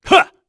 Lusikiel-Vox_Attack2_kr.wav